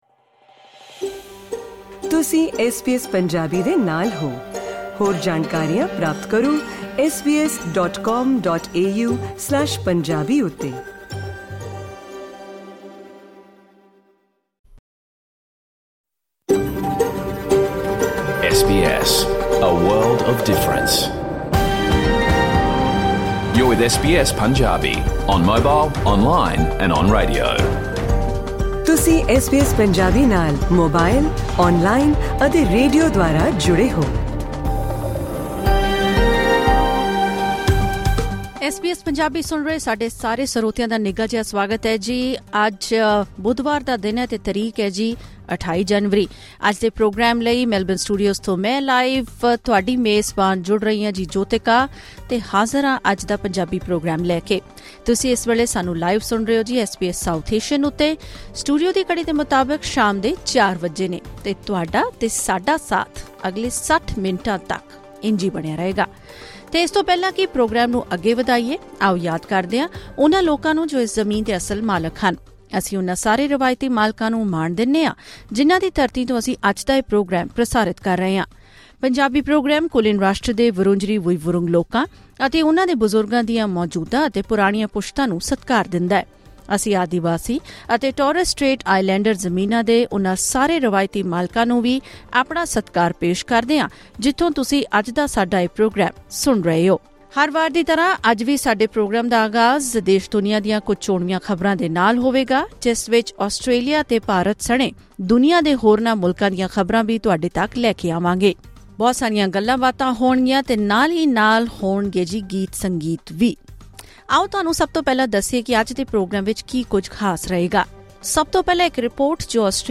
The SBS Punjabi radio program is broadcast live from Monday to Friday, from 4:00 pm to 5:00 pm.